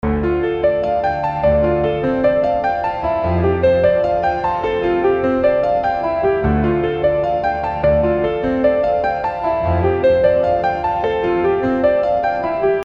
a2a_example_piano_150_8.mp3